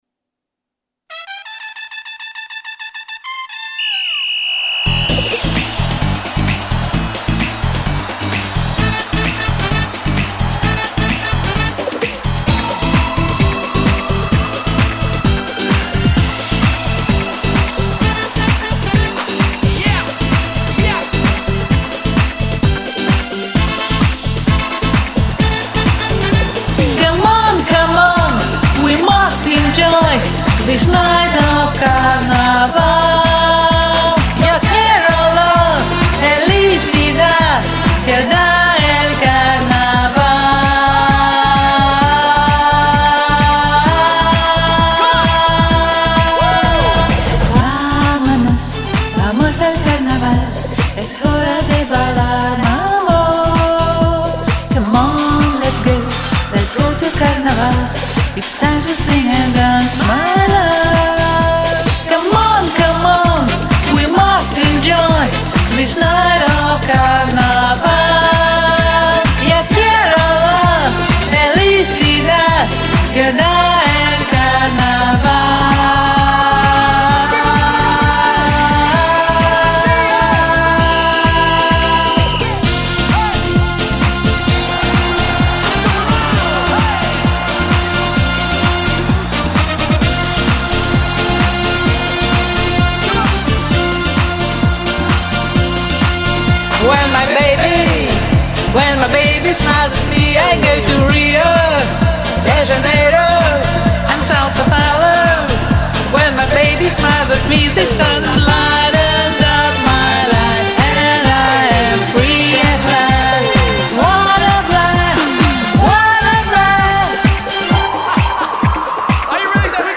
В общем, у нас с оттенком "латинос".